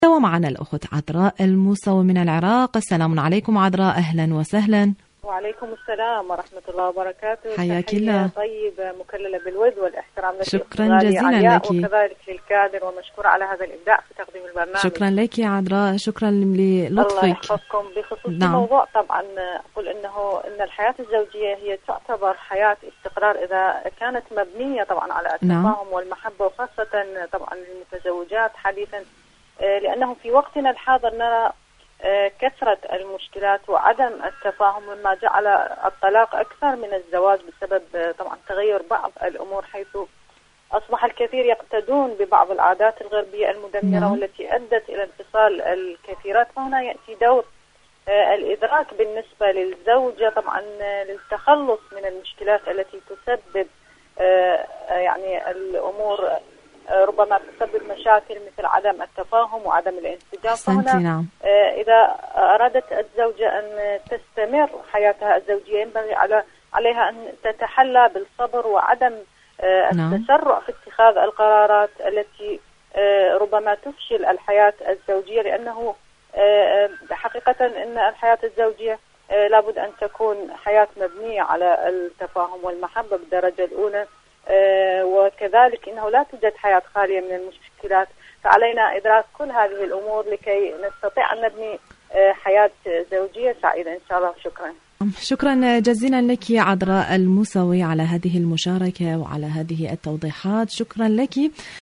مشاركة واتساب صوتية